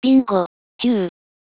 bingo_fuel.wav